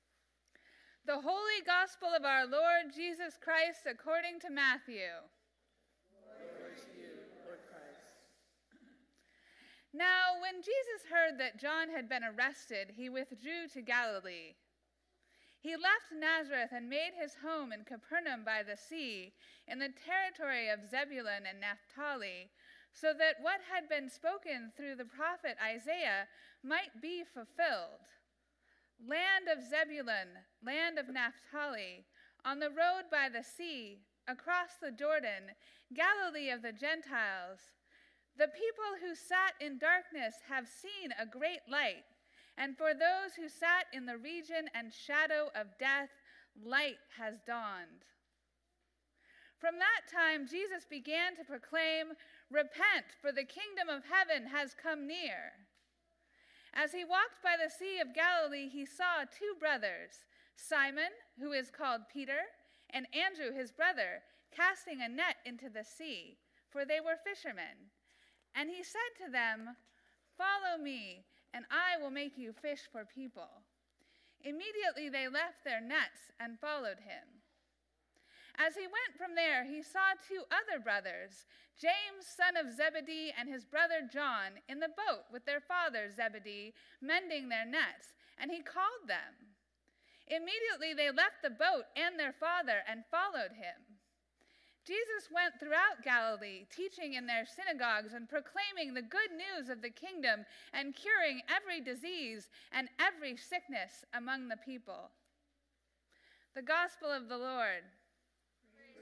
Gospel Reading: Matthew 4:12-23